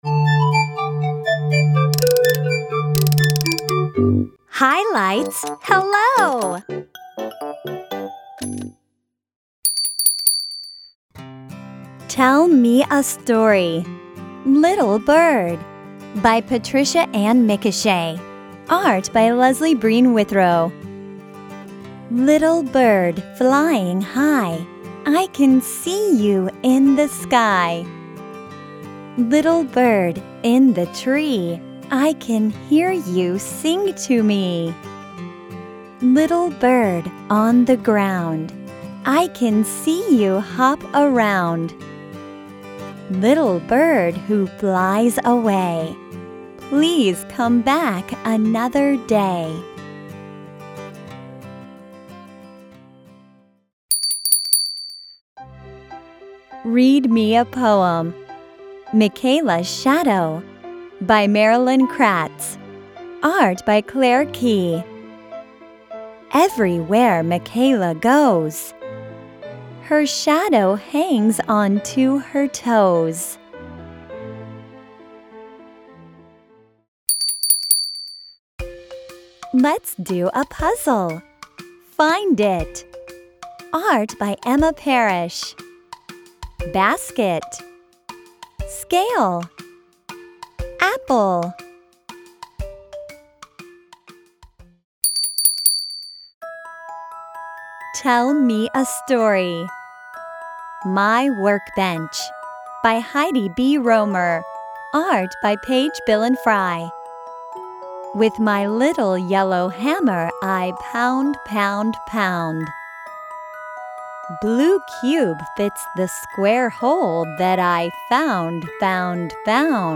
Below you will find audio narration of every book by a native English speaker.